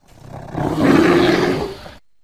BearRoar.wav